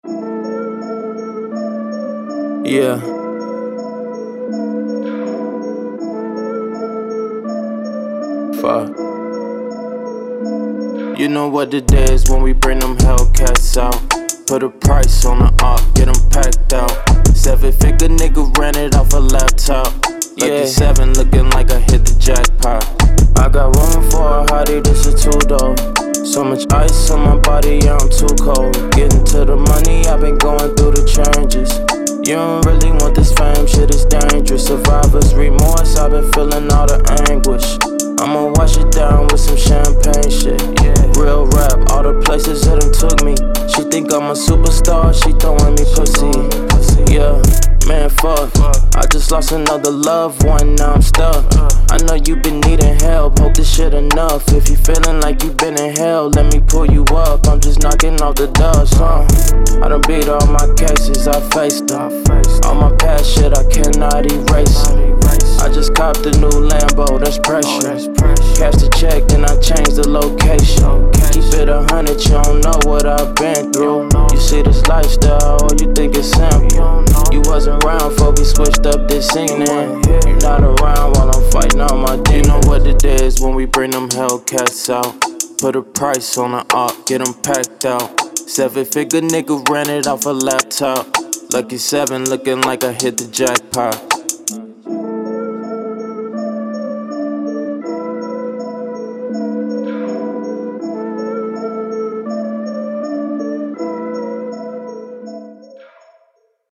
Hip Hop
A minor